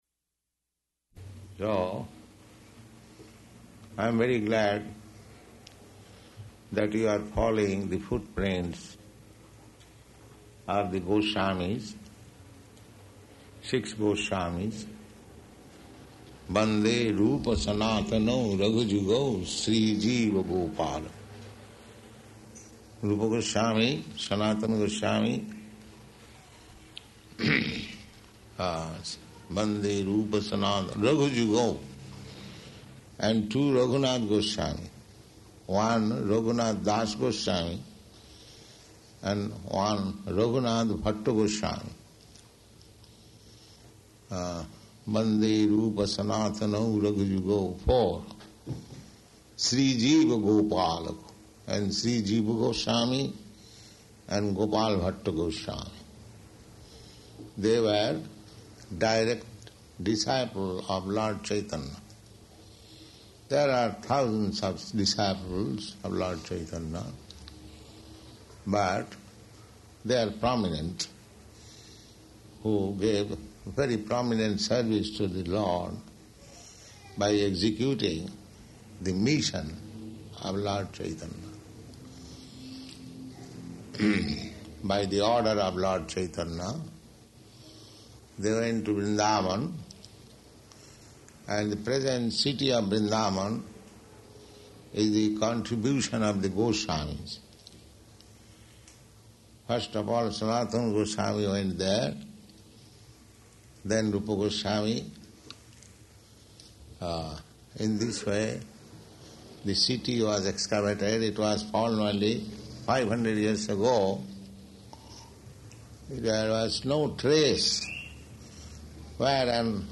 Lecture
Type: Lectures and Addresses
Location: San Francisco